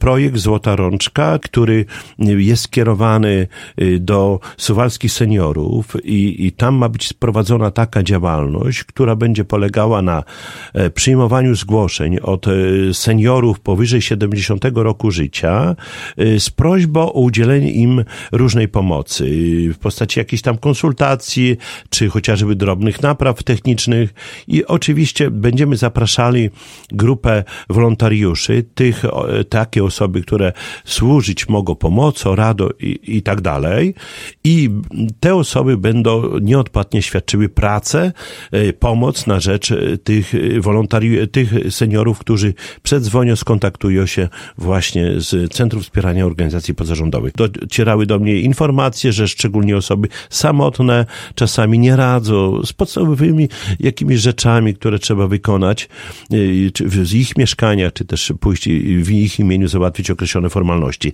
O szczegółach mówi Czesław Renkiewicz, prezydent Suwałk.